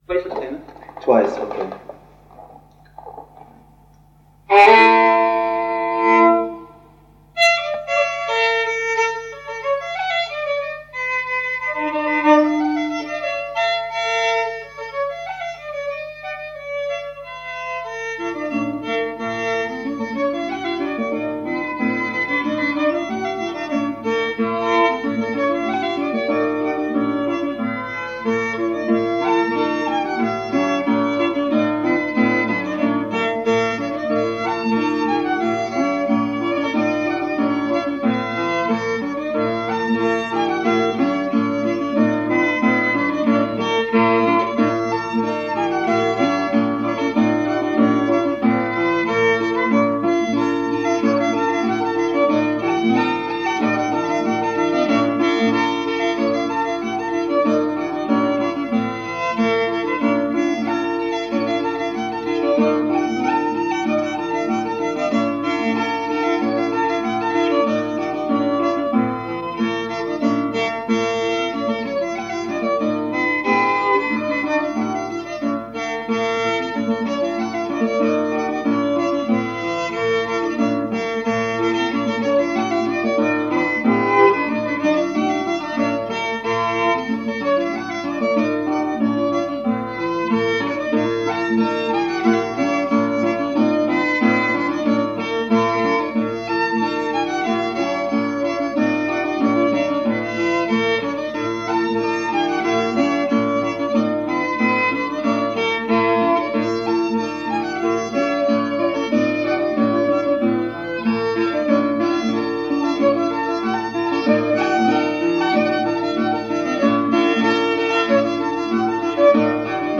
playing fiddle
King George V’s Army (march)